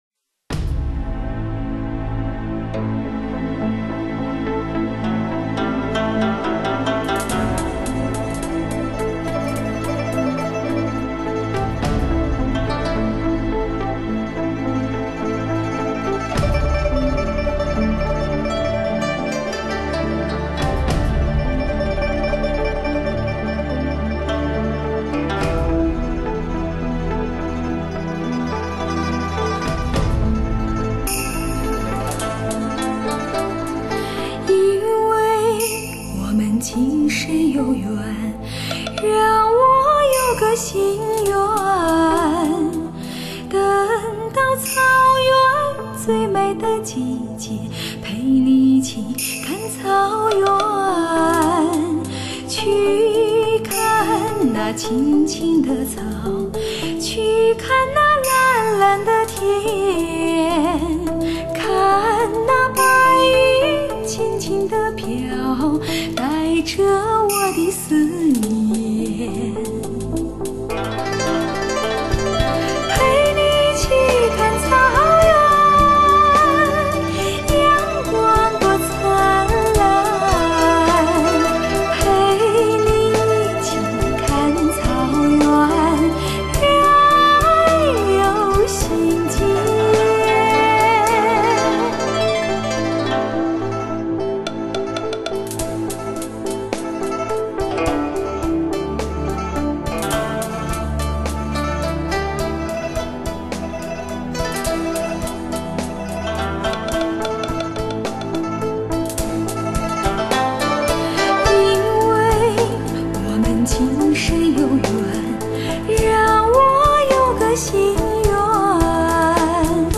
首创国际WIZOR+SRS 3D音效女歌手专辑360 °向你献唱草原最高音